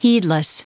Prononciation du mot heedless en anglais (fichier audio)
Prononciation du mot : heedless